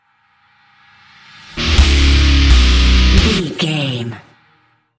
Aeolian/Minor
drums
electric guitar
bass guitar
hard rock
lead guitar
aggressive
energetic
intense
powerful
nu metal
alternative metal